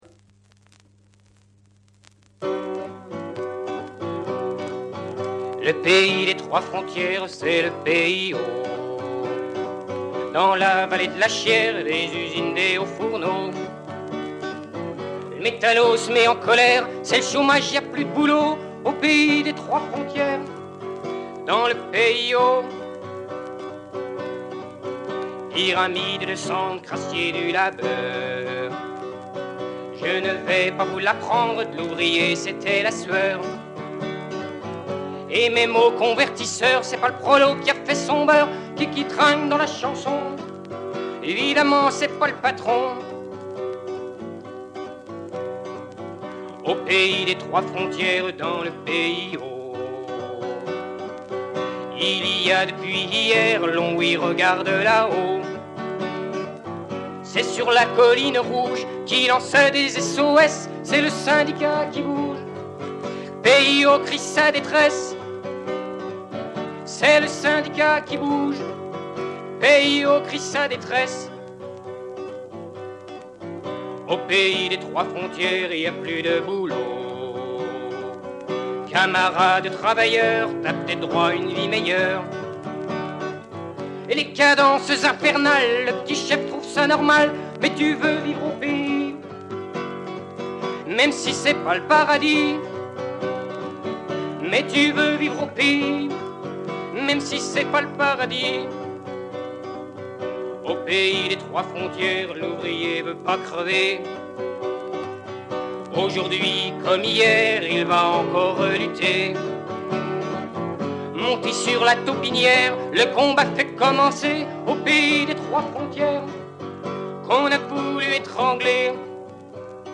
Disque 45 tours datant de 1979
avec des militants,
avec des travailleurs.